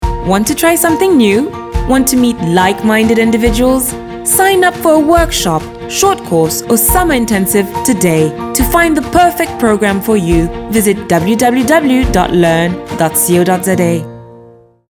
Vocal Styles:
cultured, elegant, polished, refined
Vocal Age:
My demo reels